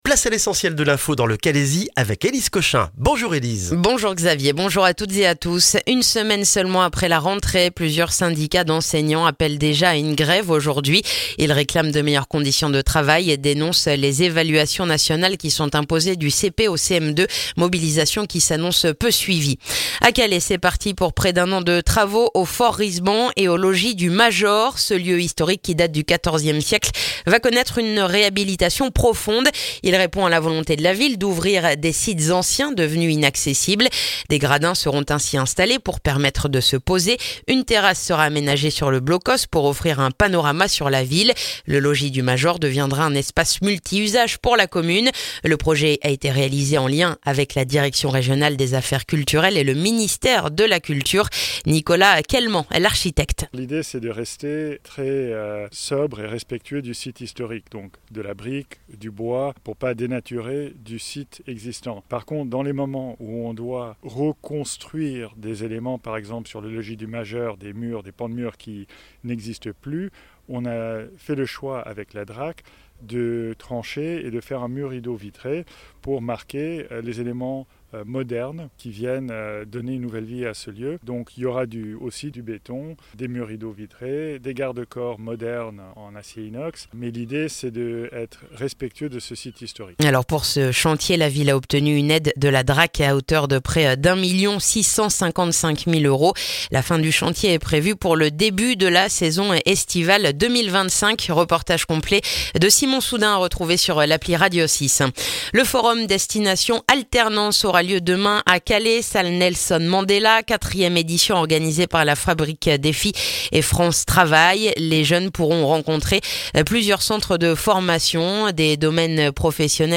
Le journal du mardi 10 septembre dans le calaisis